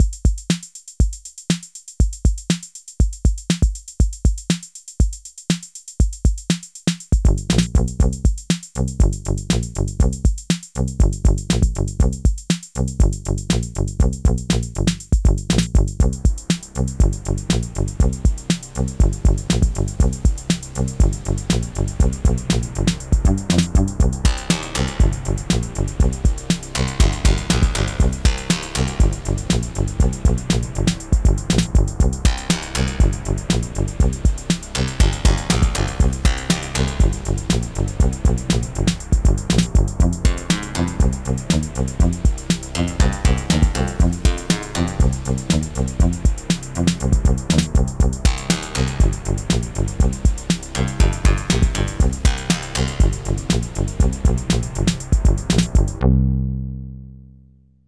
It has a campy, retro feel, like the theme song for a spy thriller TV show that doesn’t take itself too seriously.